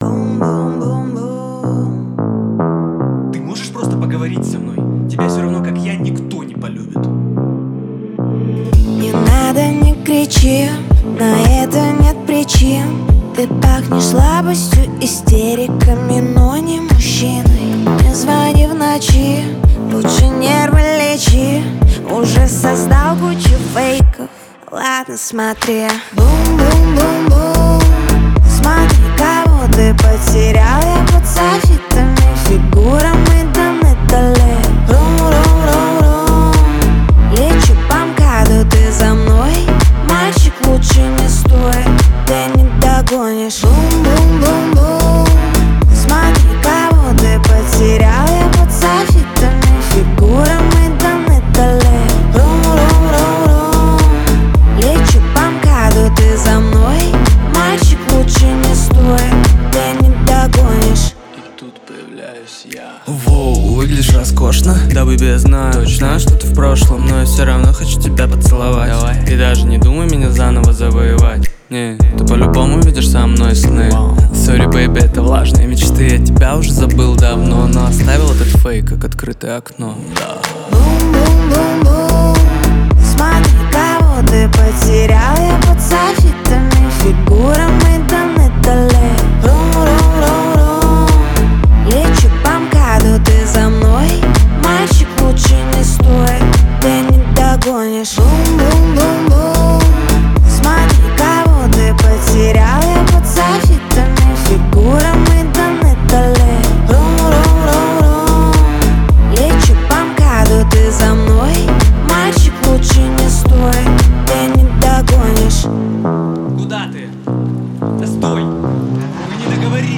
• Качество MP3: 320 kbps, Stereo